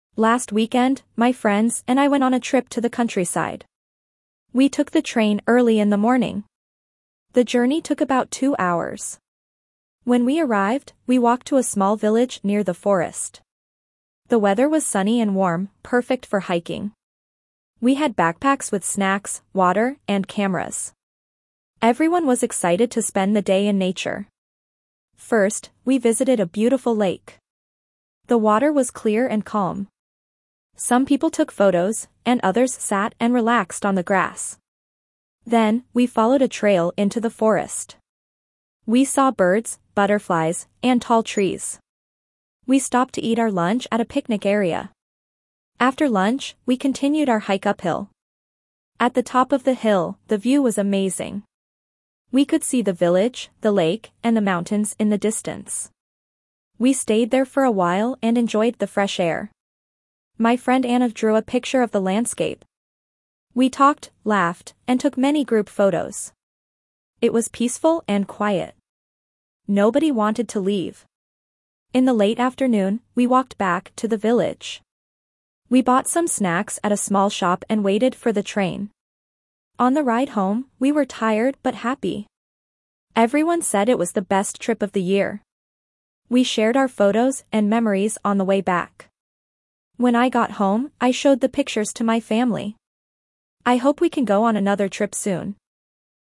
Reading A2 - A Weekend Trip
1.-A2-Reading-A-Weekend-Trip.mp3